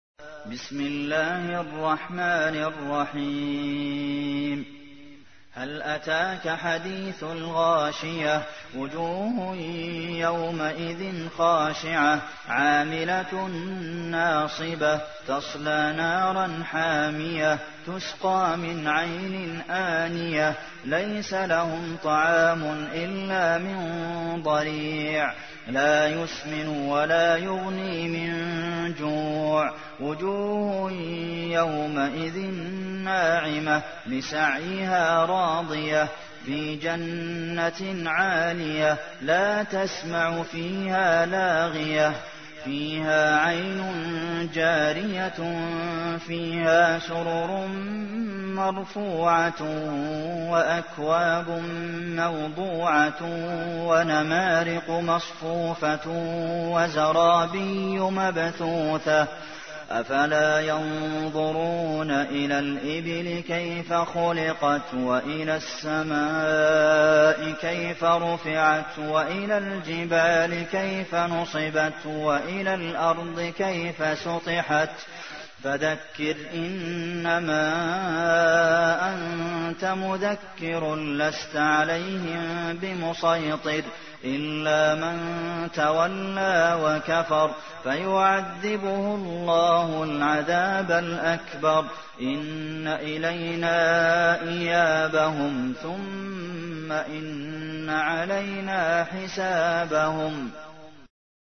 تحميل : 88. سورة الغاشية / القارئ عبد المحسن قاسم / القرآن الكريم / موقع يا حسين